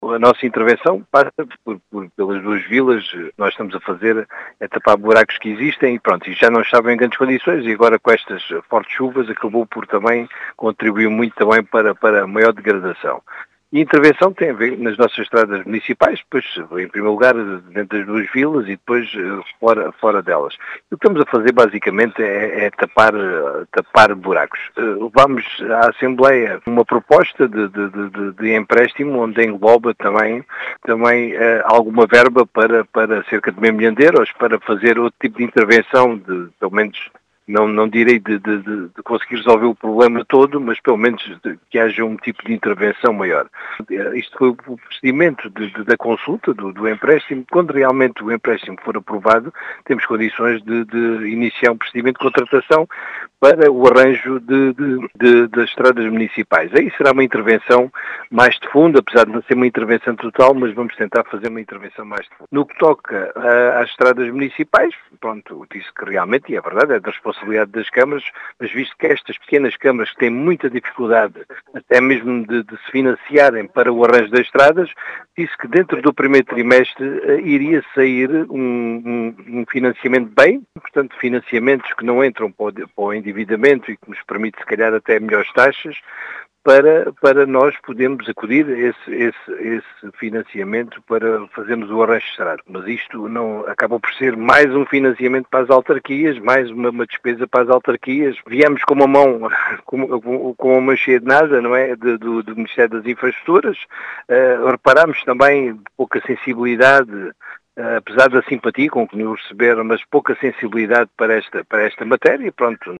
As explicações são do presidente da Câmara de Alvito, José Efigénio, que realça a importância desta intervenção, e nas dificuldades de financiamento das autarquias, apontando “falta de sensibilidade” nesta matéria ao ministro das Infraestruturas.